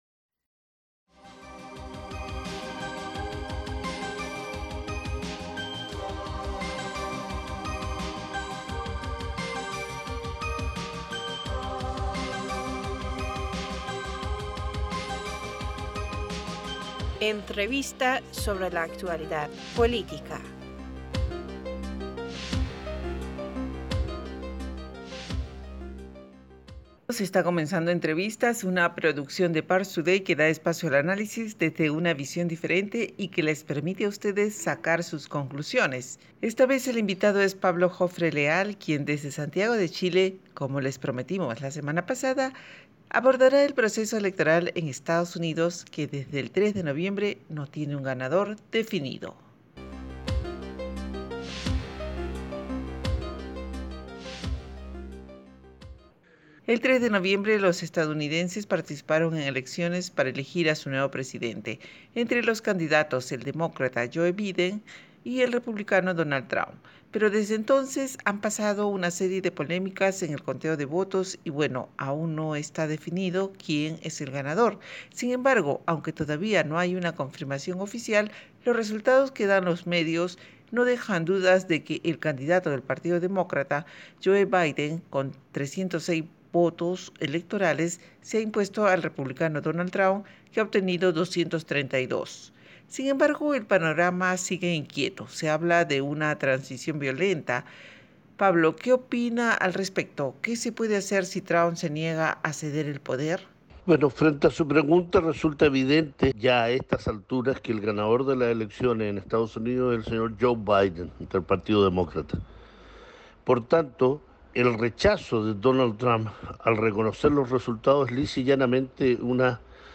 ParsToday- Entrevistador (E): Bienvenidos está comenzando Entrevistas, una producción de ParsToday que da espacio al análisis desde una visión diferente y que les permite a ustedes sacar sus conclusiones.